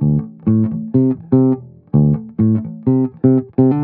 21 Bass Loop D.wav